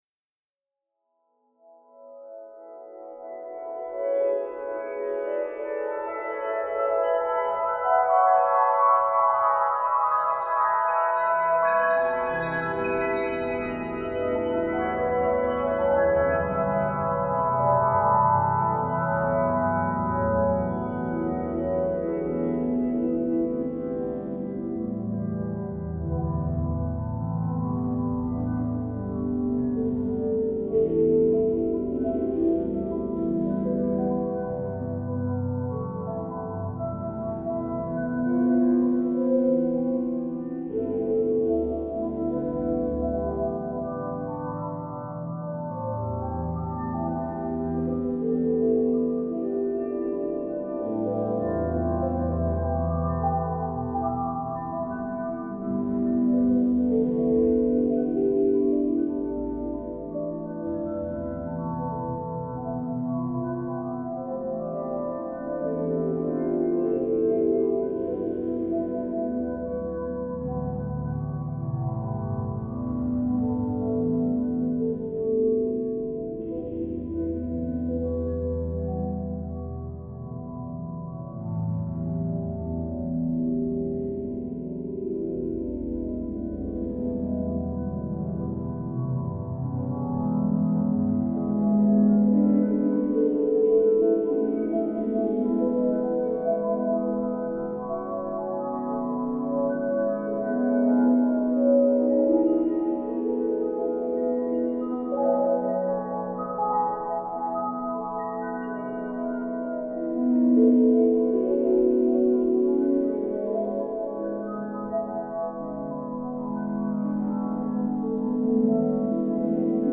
Piano-samples